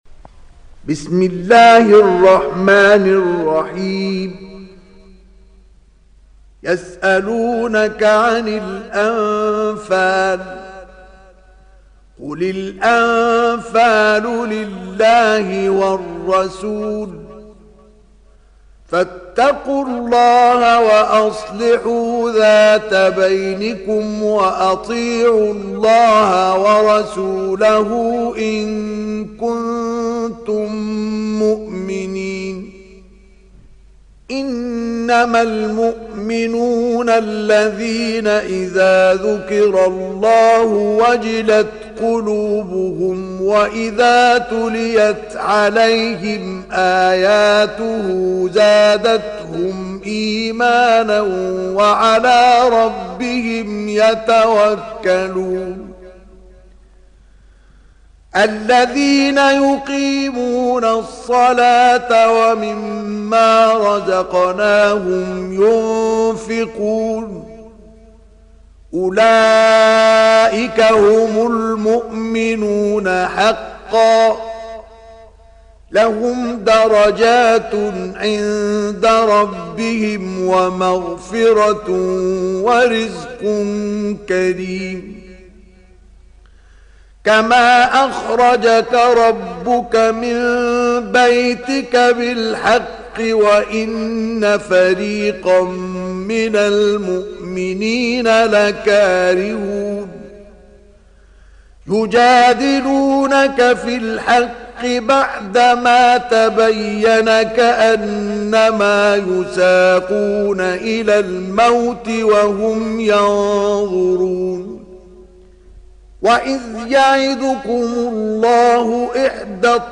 تحميل سورة الأنفال mp3 بصوت مصطفى إسماعيل برواية حفص عن عاصم, تحميل استماع القرآن الكريم على الجوال mp3 كاملا بروابط مباشرة وسريعة